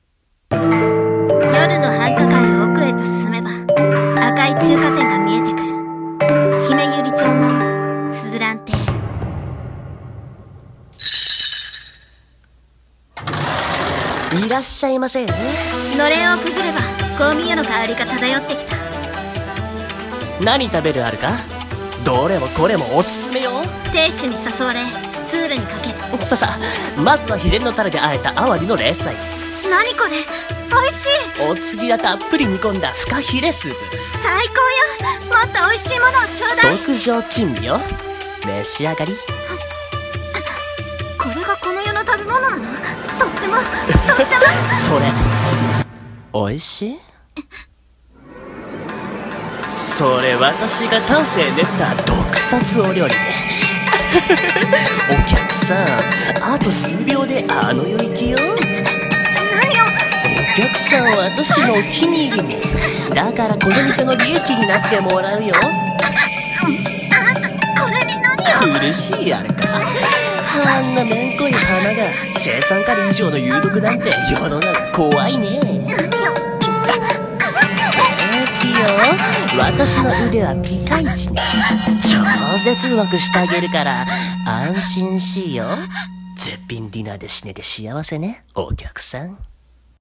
【声劇】珍味の鈴蘭中華店